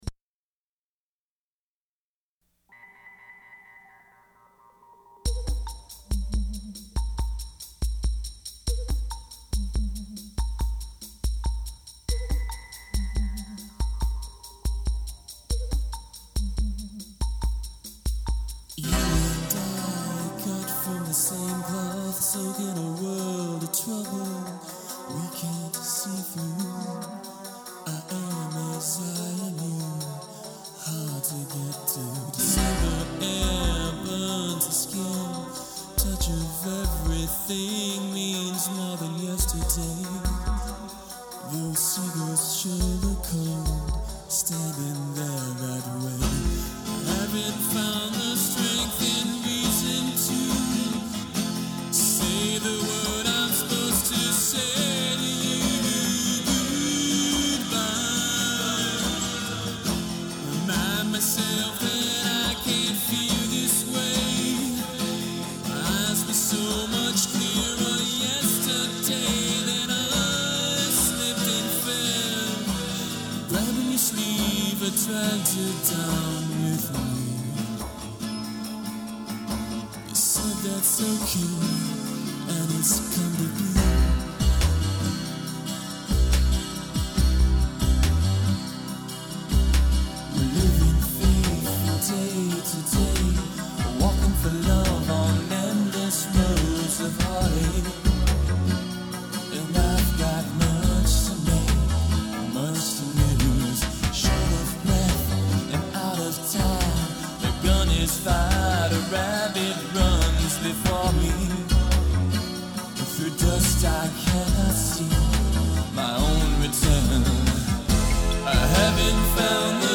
on 8 track